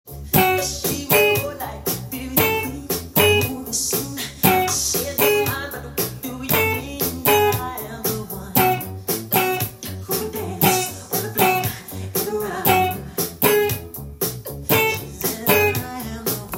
カッティングtab譜
譜面通り音源にあわせて弾いてみました